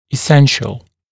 [ɪ’senʃl] [и’сэншл] важнейший, необходимый, основной